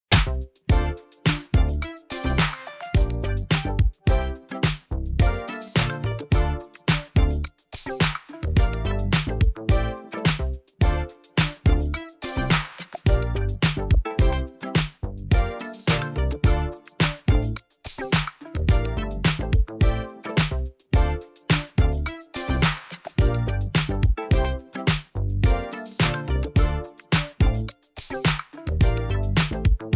Music Ringtones